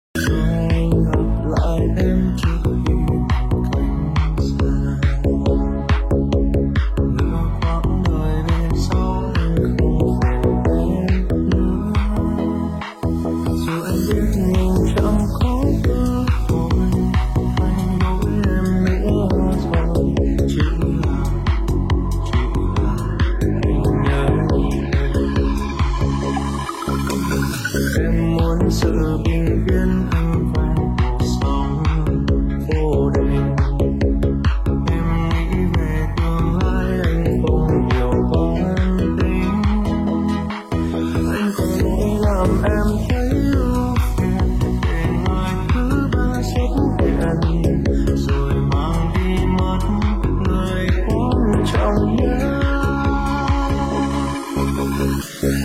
Nhạc Chuông TikTok